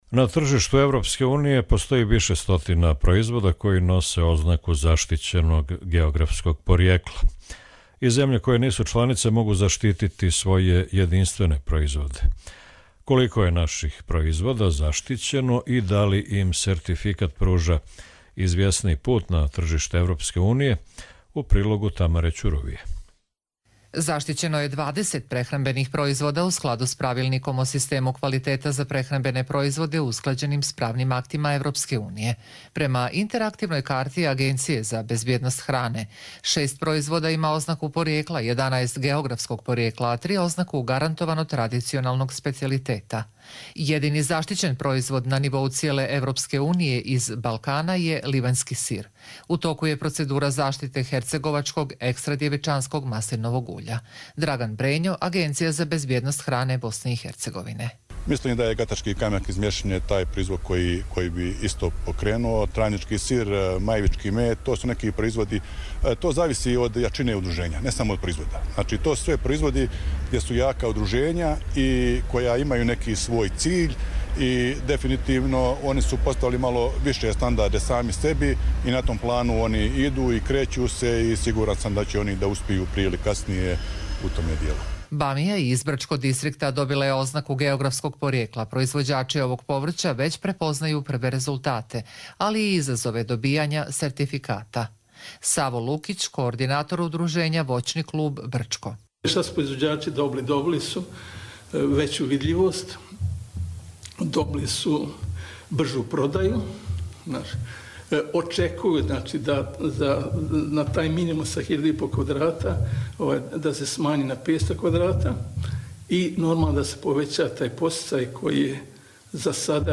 Radio reportaža